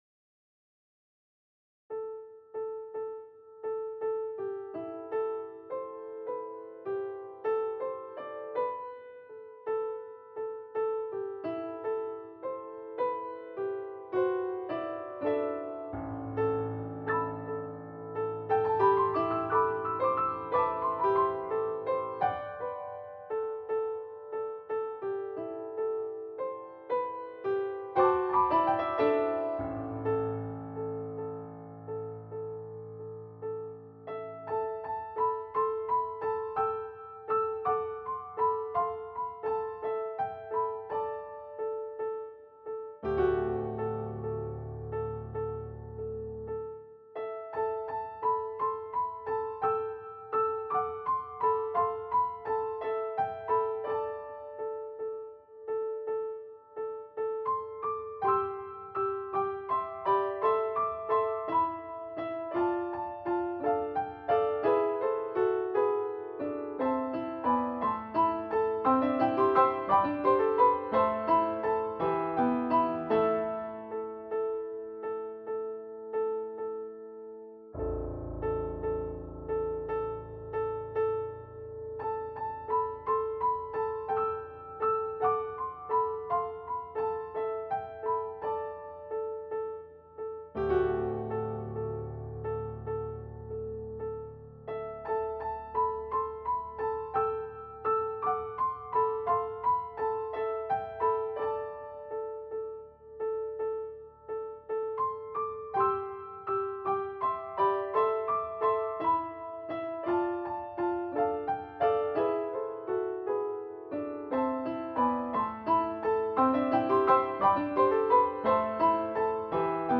A solo piano interpretation of the old Christian folk song, a la seafaring music.
Voicing/Instrumentation: Piano Solo